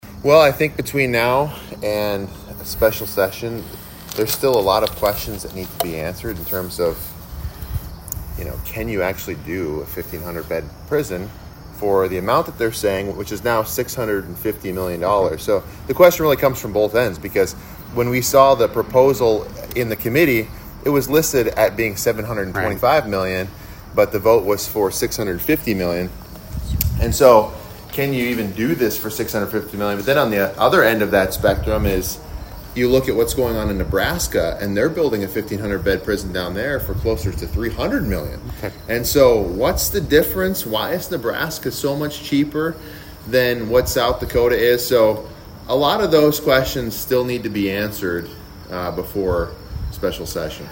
HubCityRadio had an opportunity to do an interview with him to address several different topics.